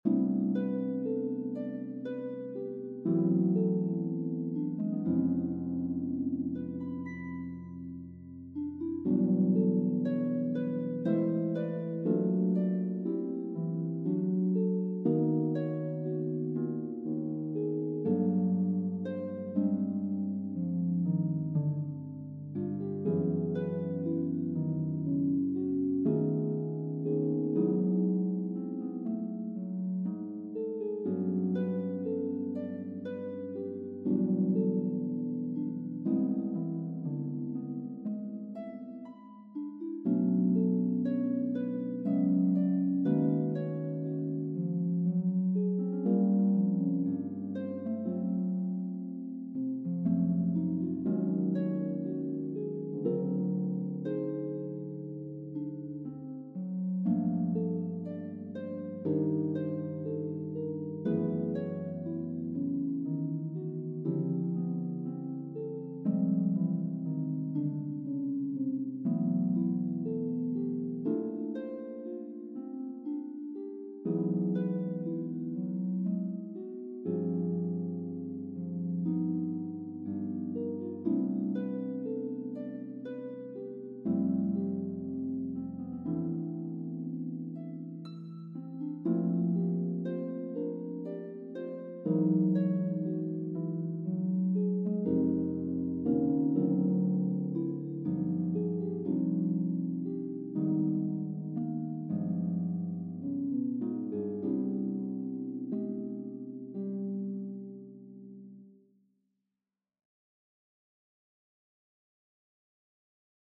I was getting rather bored with nothing to do, so I thought I would do a bit more writing, even though the soundfiles have to be played by my music notation software.
I also recorded this with harp rather than piano as the piano sound on my software is not good. It is obviously not suited to be played by a harp.